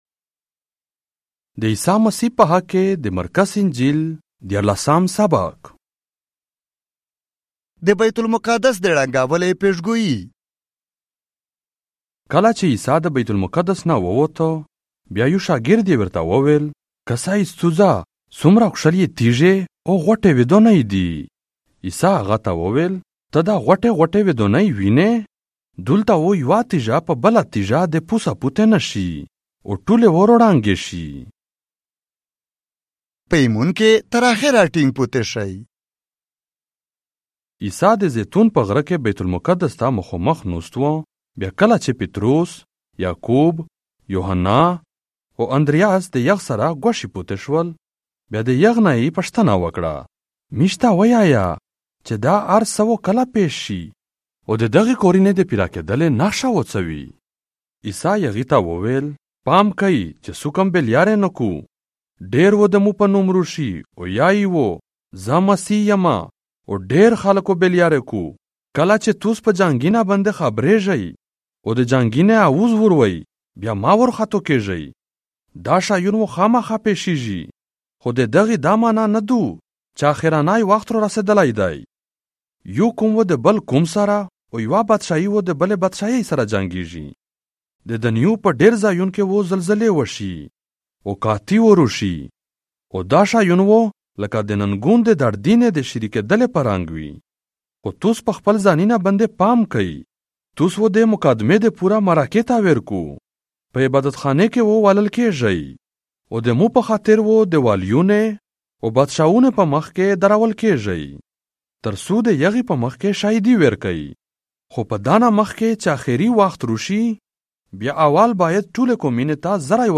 دې عيسیٰ مسيح په حق کې دې مرقوس انجيل - ديارلاسام ساباق، په پشتو ژبه، مرکزي (آډیو) ۲۰۲۵